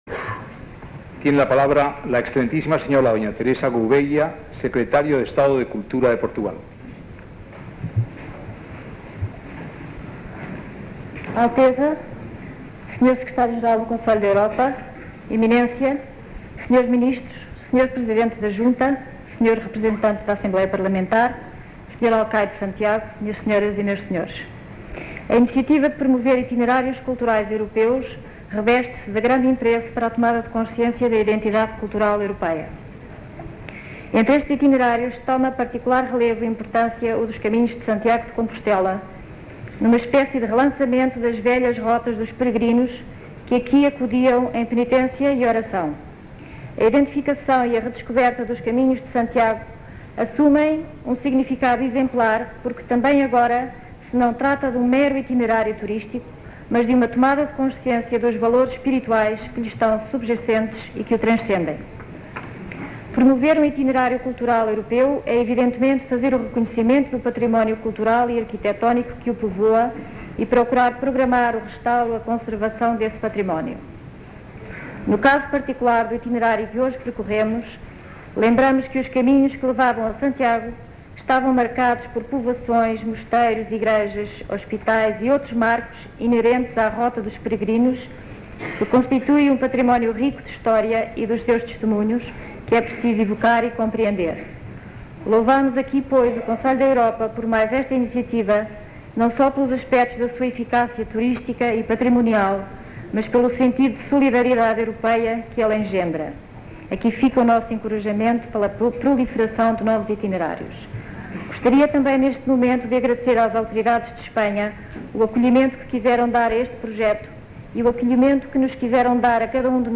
Discurso de Dña. Teresa Guveia, Secretaria de Estado de Cultura de Portugal
Acto de proclamación del Camino de Santiago como Itinerario Cultural Europeo. 1987